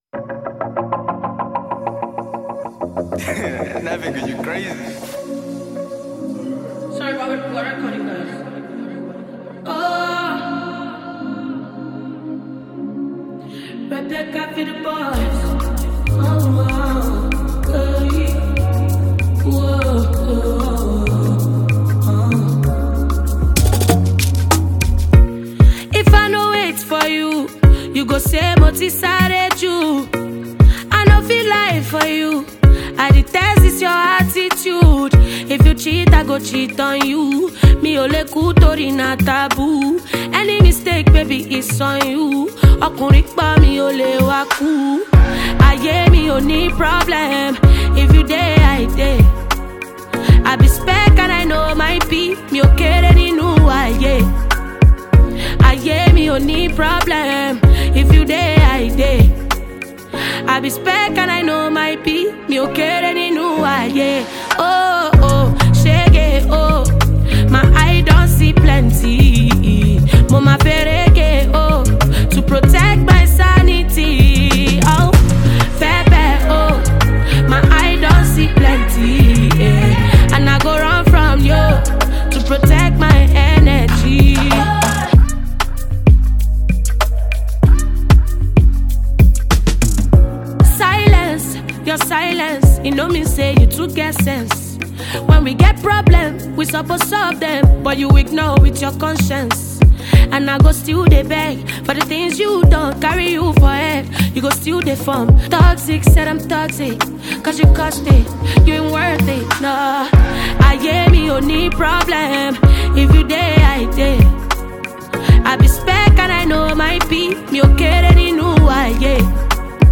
Well renowned Nigerian artist and performer
thrilling new gbedu song